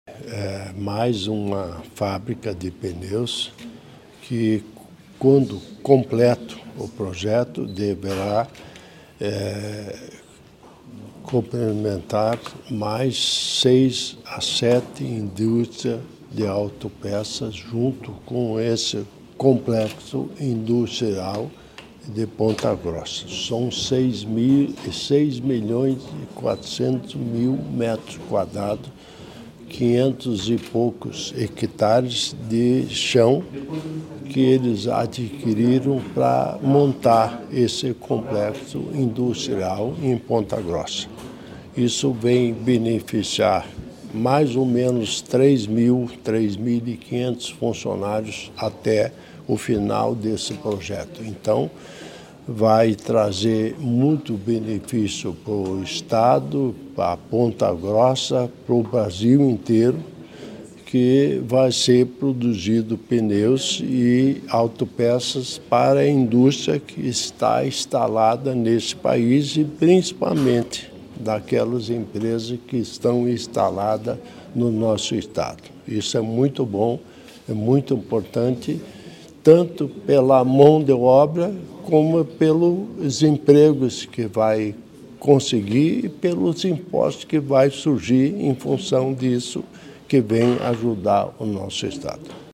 Sonora do governador em exercício Darci Piana sobre a instalação da nova fábrica de pneus da XBRI Pneus e da multinacional chinesa Linglong Tire em Ponta Grossa